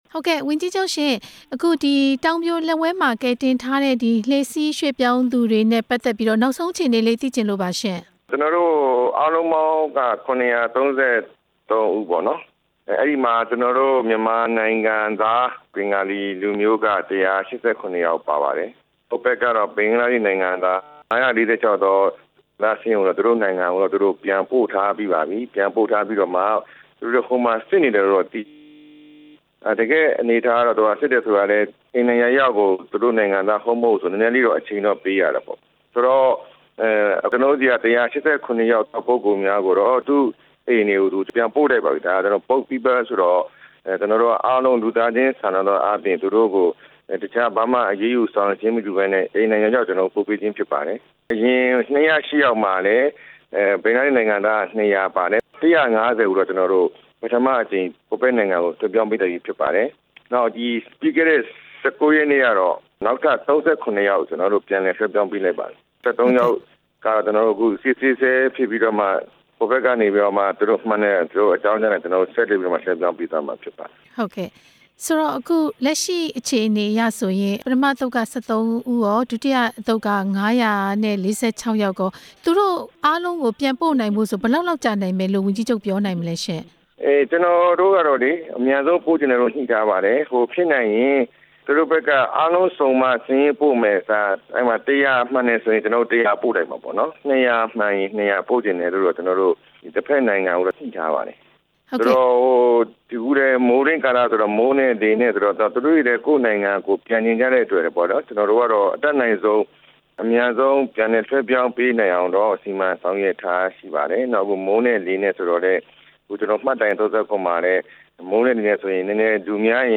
လှေစီးရွှေ့ပြောင်းသူ ပြန်ပို့ရေး ရခိုင်ပြည်နယ်ဝန်ကြီးချုပ်နဲ့ မေးမြန်းချက်